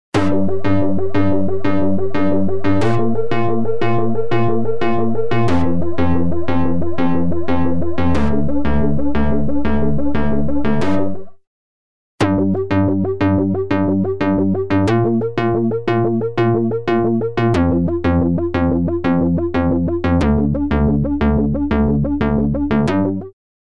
UltraTap | Synth | Preset: Huh Flange
UltraTap-Synth-HuhFlange-Wet-Dry.mp3